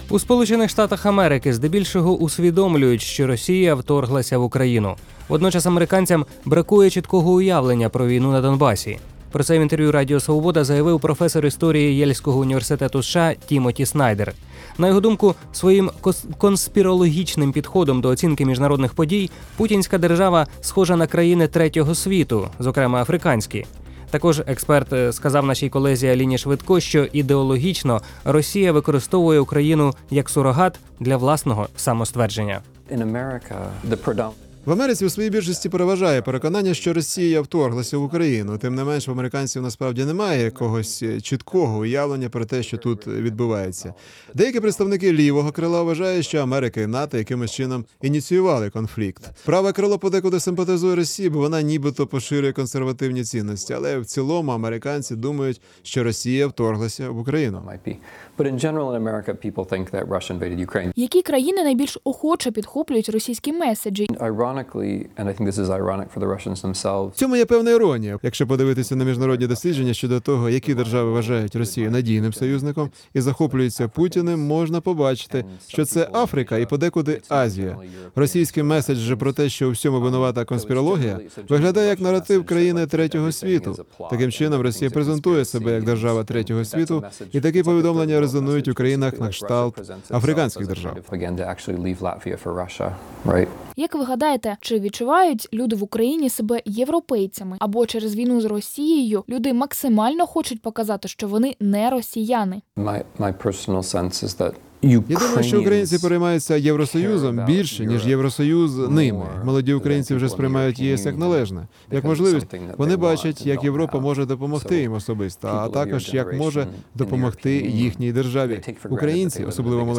У США здебільшого усвідомлюють, що Росія вторглася в Україну. Водночас американцям бракує чіткого уявлення про війну на Донбасі. Про це в інтерв’ю Радіо Свобода заявив професор історії Єльського університету США, автор книги «Криваві землі: Європа між Гітлером і Сталіним» Тімоті Снайдер.